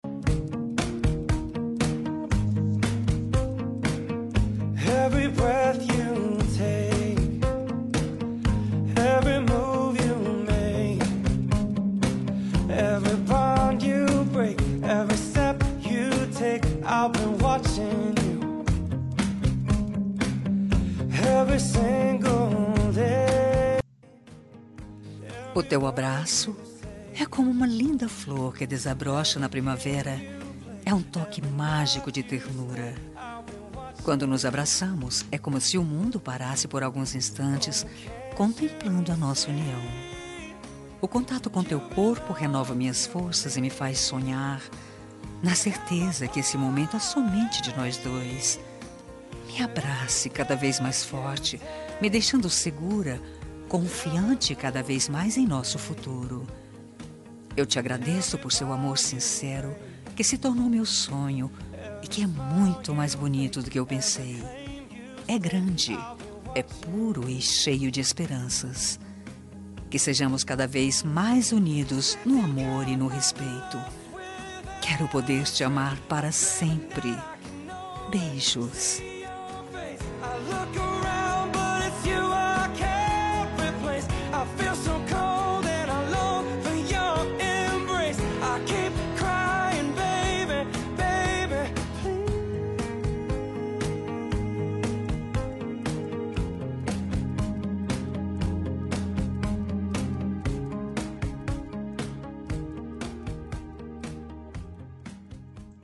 Romântica para Marido- Voz Feminina – Cód: 6719